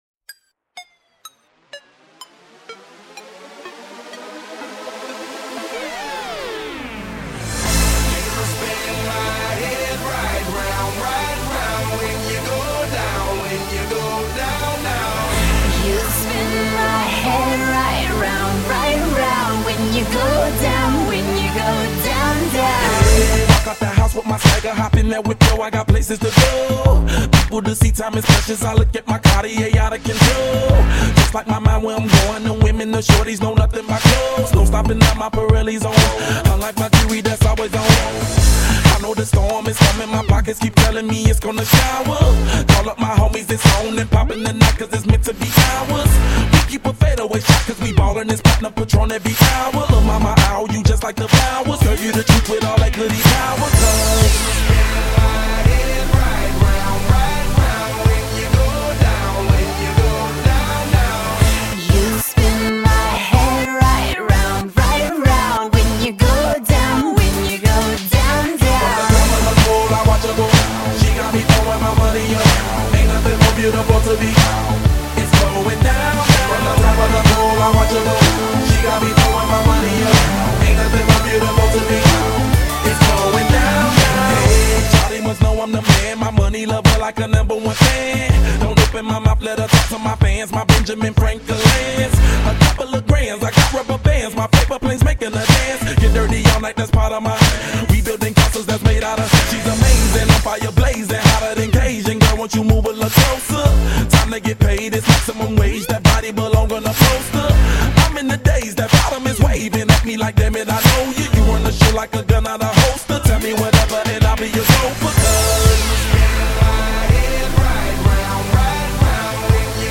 Track7_Pop.mp3